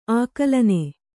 ♪ ākalane